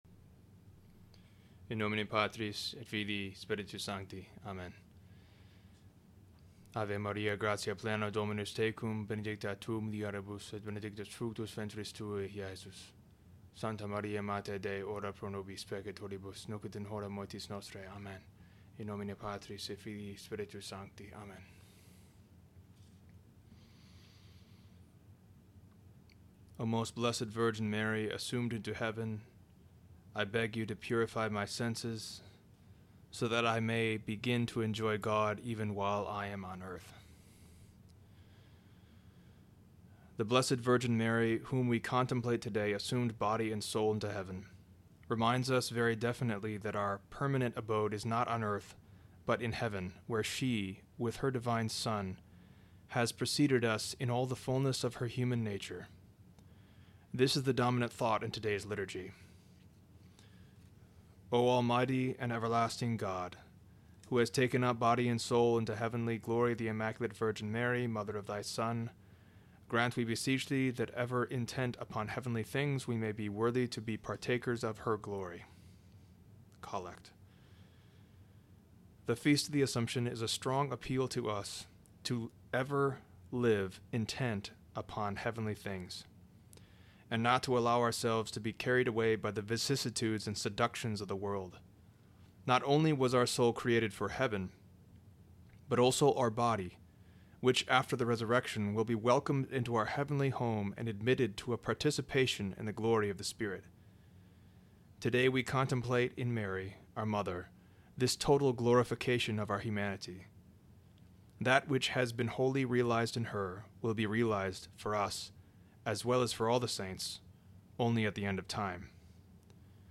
Assumption of Mary - Divine Intimacy Reading